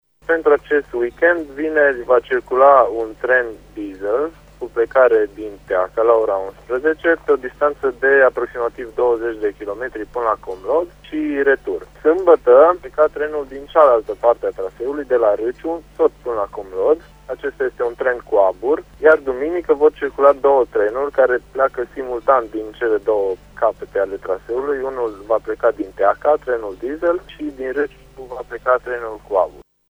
în emisiunea Pulsul Zilei